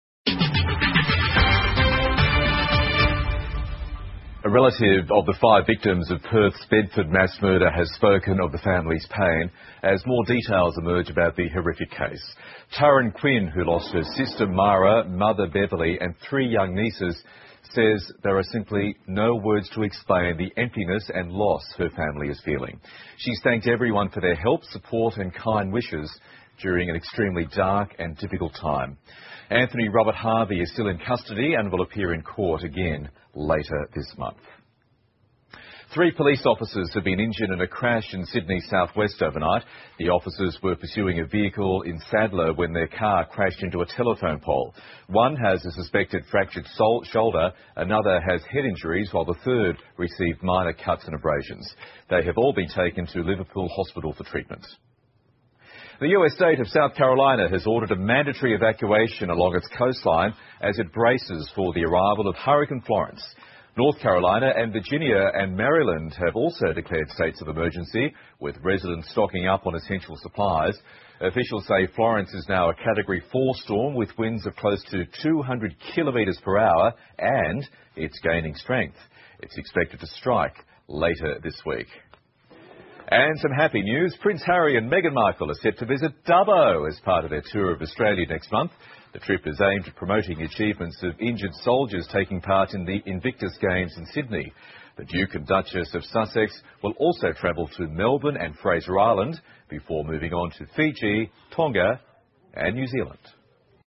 澳洲新闻 (ABC新闻快递) 飓风佛罗伦斯即将登陆美国东海岸 哈里王子10月携妻访澳 听力文件下载—在线英语听力室